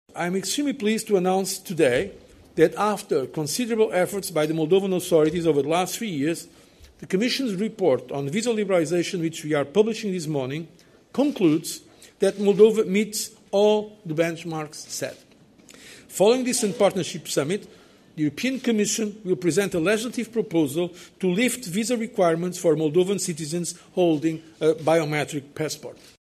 Anunțul a fost făcut de José Manuel Barroso la o conferință de presă comună cu premierii Iurie Leancă și Victor Ponta, la Bruxelles.
Declarația lui José Manuel Barroso la Conferința de presă de la Bruxelles